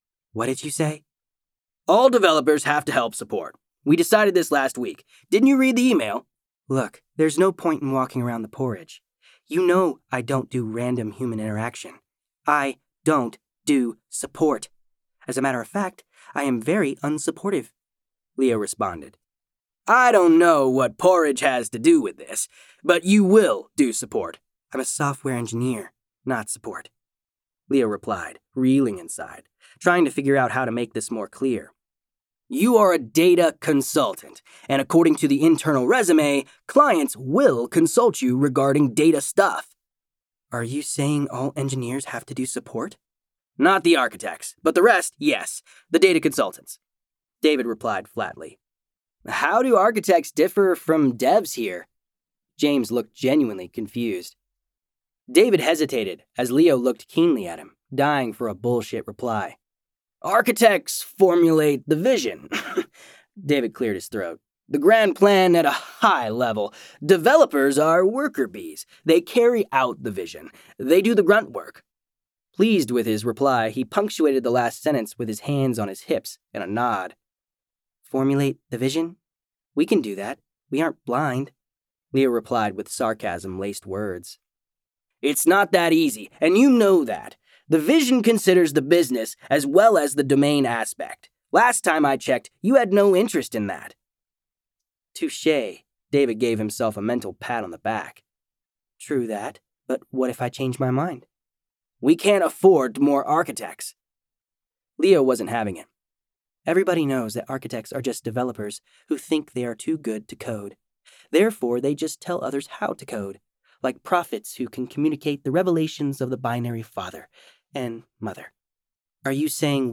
sample_audiobook.mp3